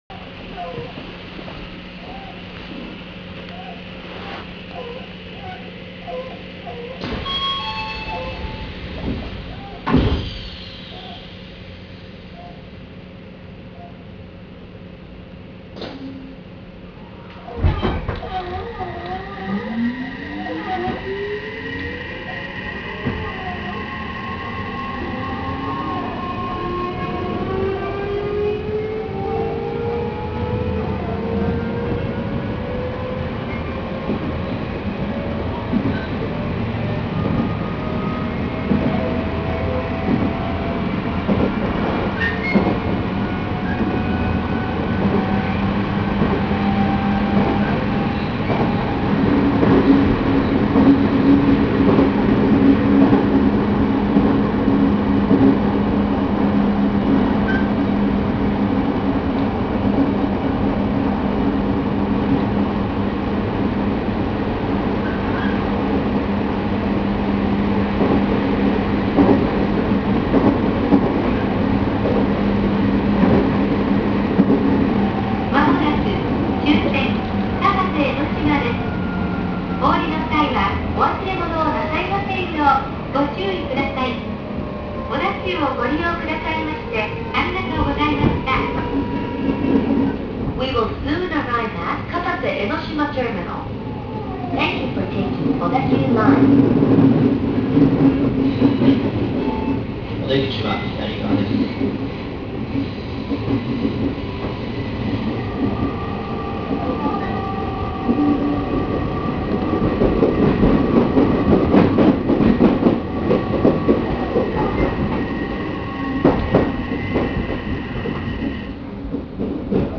・3000形（三菱IGBT 後期車）走行音
【江ノ島線】鵠沼海岸→片瀬江ノ島（2分42秒：883KB）
一方こちらは後期に導入された3000形のモーター音。三菱IGBTであること自体は変わりませんが、全密閉モーターを採用したことにより、また走行音が変わりました。